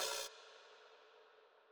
Open Hats / Noisy Open Hat